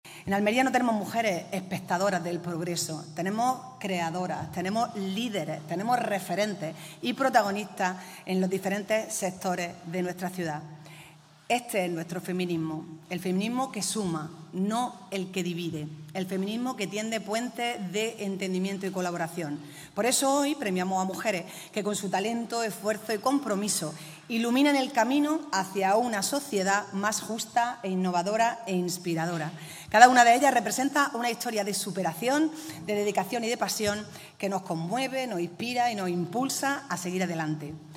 La alcaldesa, María del Mar Vázquez, afirma que “en el Ayuntamiento ponemos la igualdad real en el centro de cada decisión”
ALCALDESA-PREMIOSS-8M.mp3